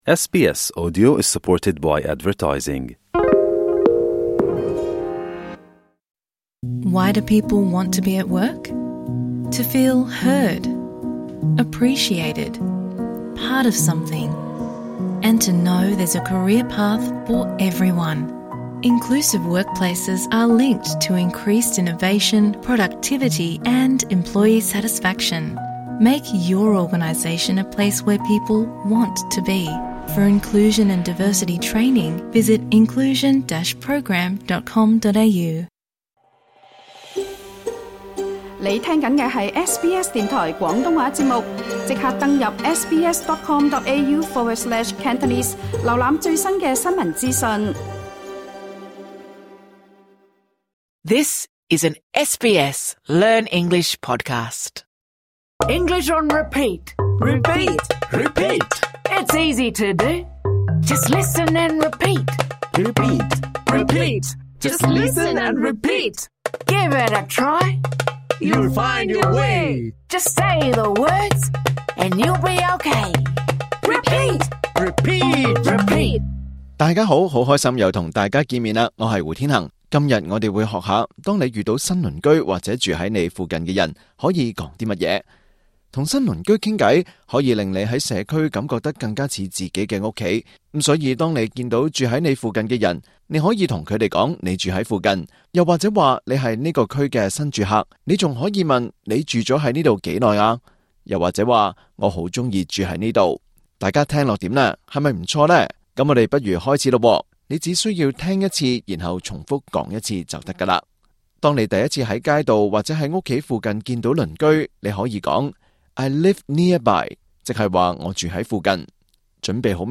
今集為初學者設計的簡單課程。